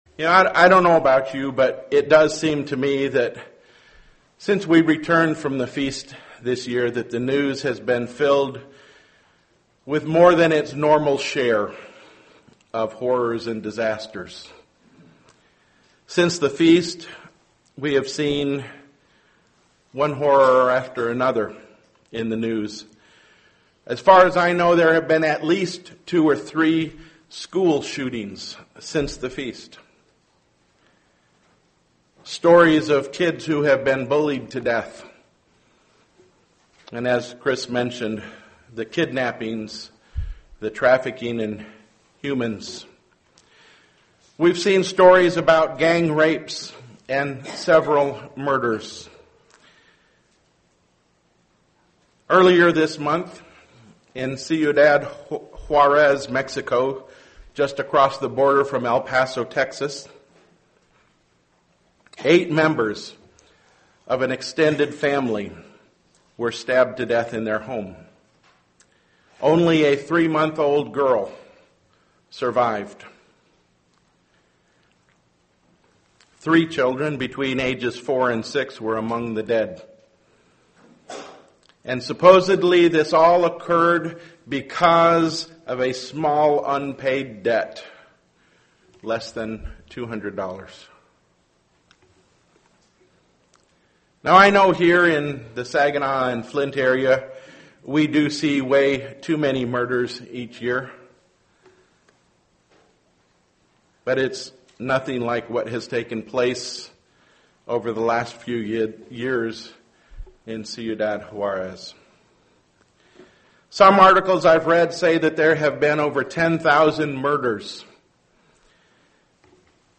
Given in Flint, MI
UCG Sermon Studying the bible?